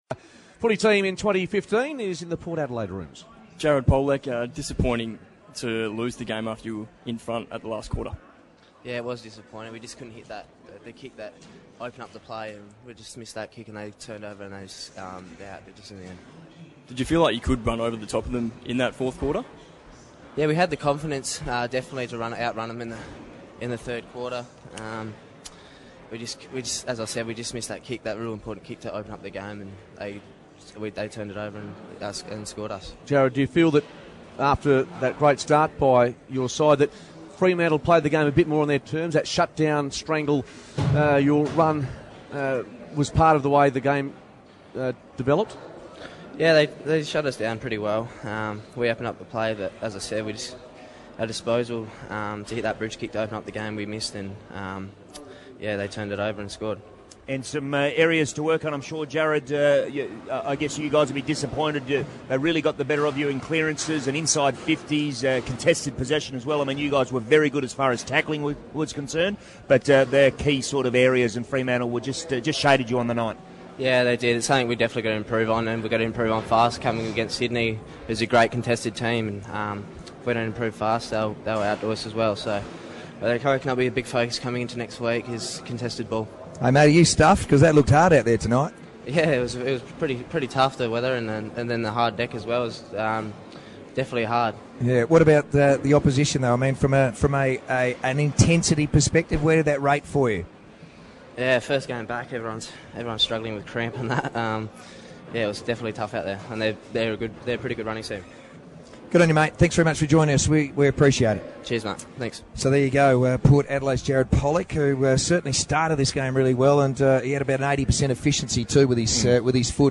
The Power's Jared Polec speaks after their seven point loss